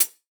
Teck-hihat (6).wav